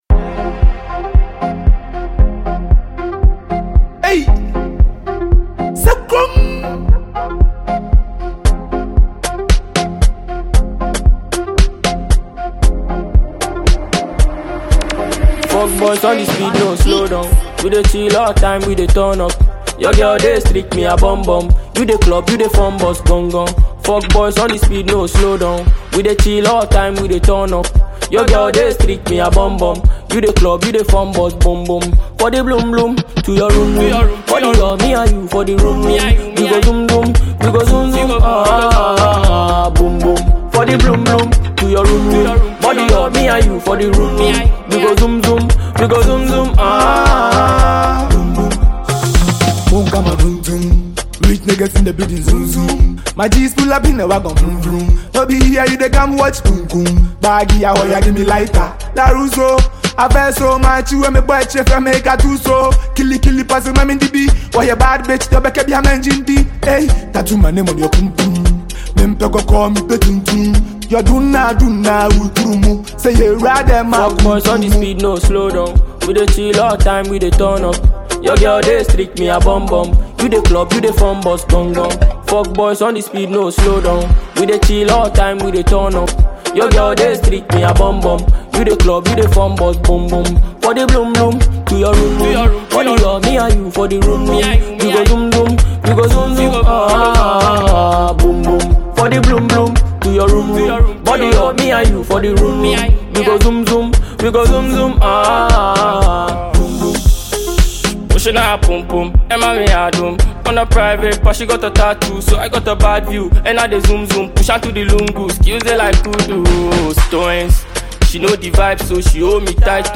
gbedu song